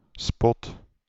Ääntäminen
IPA: /spɔt/